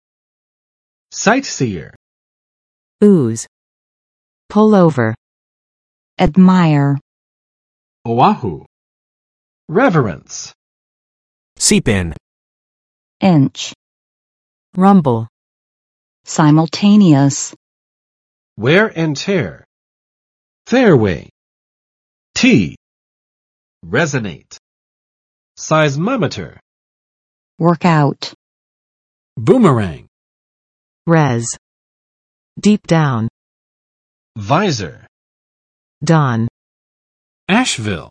[ˋsaɪt͵siɚ] n. 观光者，游客